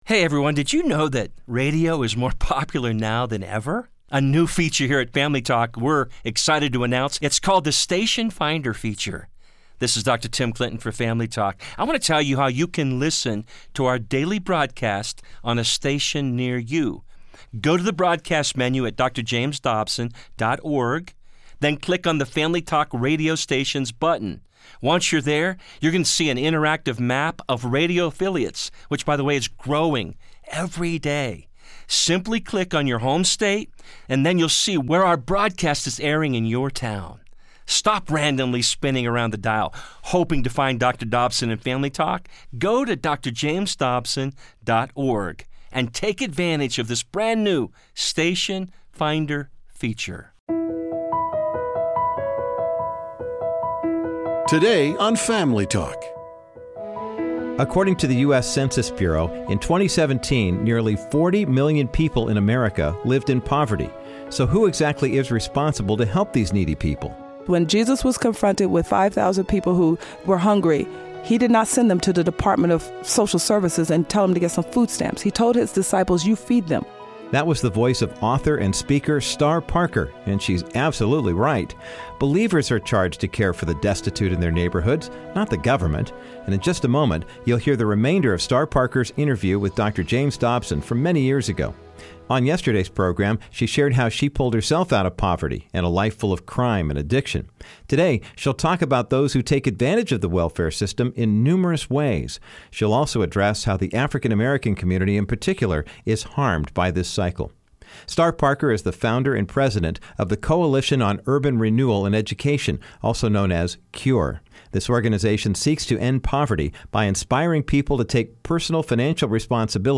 Dr. Dobson continues addressing the welfare crises by interviewing author and speaker Star Parker. She explains how economic programs, like food stamps or low-income housing, actually hurt minorities in America.